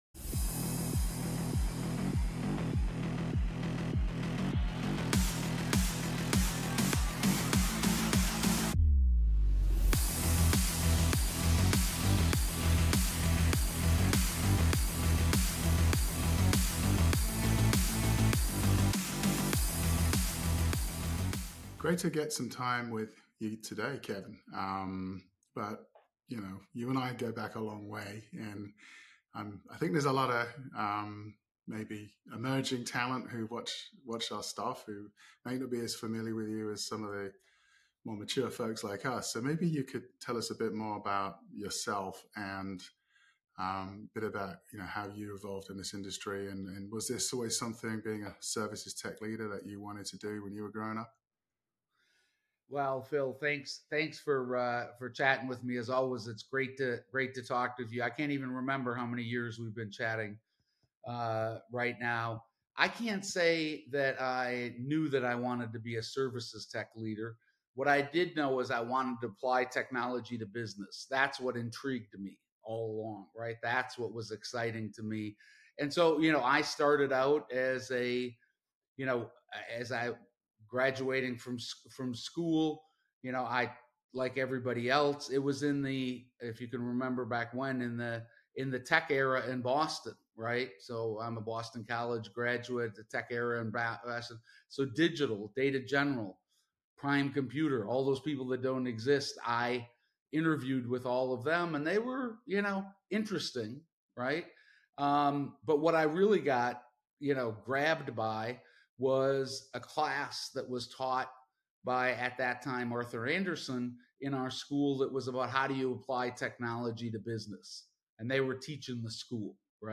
Fireside Chat | HFS and Syniti discuss the importance of data foundations